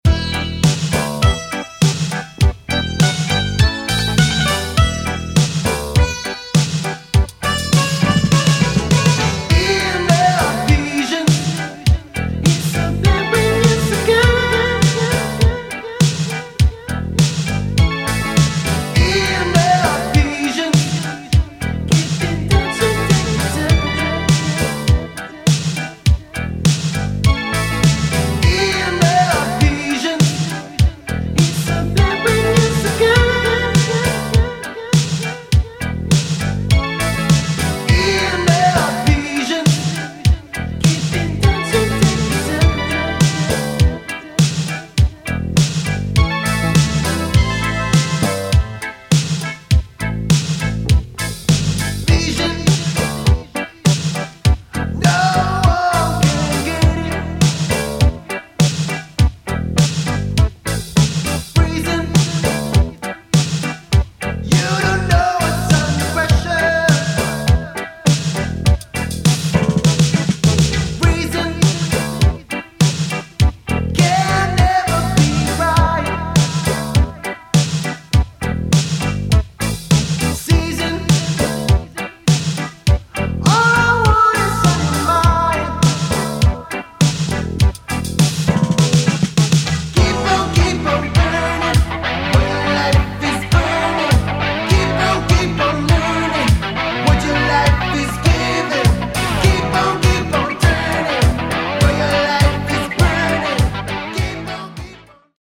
Some serious oldskool beat lessons here...